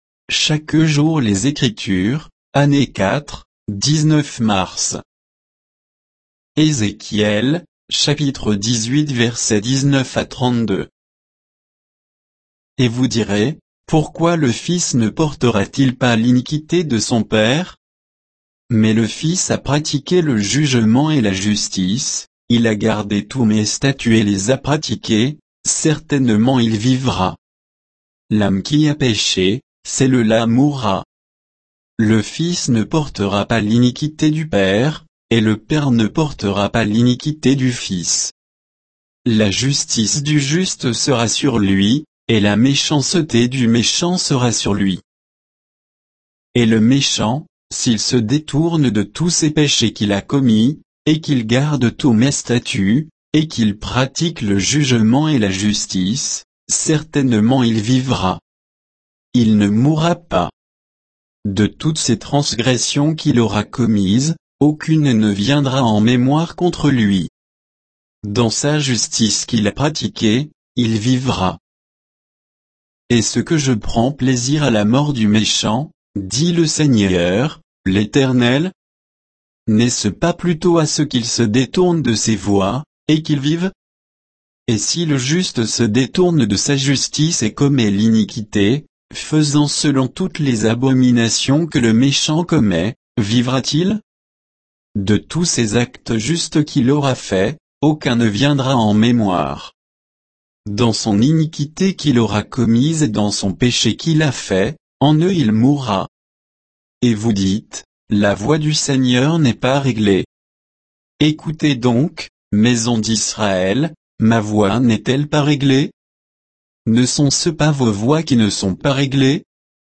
Méditation quoditienne de Chaque jour les Écritures sur Ézéchiel 18, 19 à 32